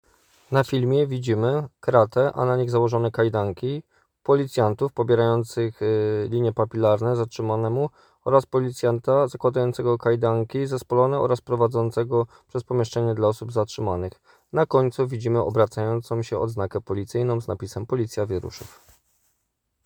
Nagranie audio Audideskrypcja_znaczna_ilos__.m4a